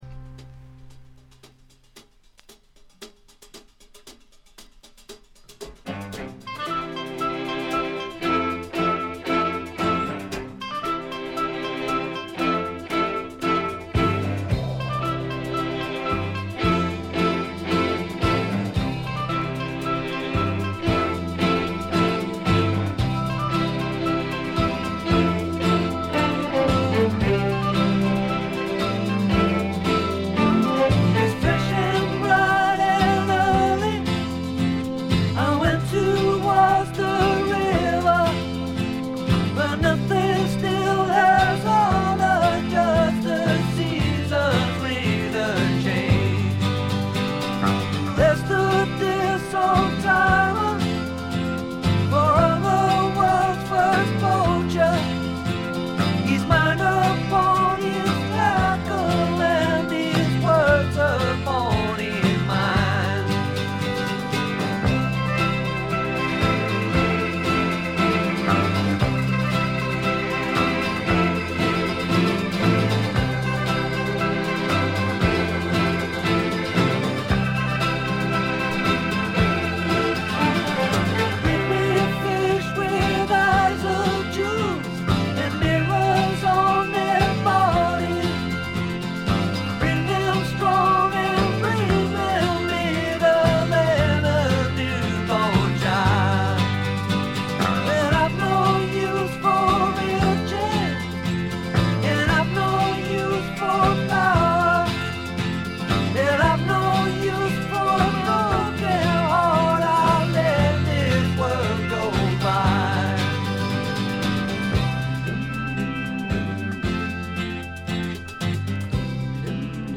ほとんどノイズ感無し。
試聴曲は現品からの取り込み音源です。
Mixed at IBC.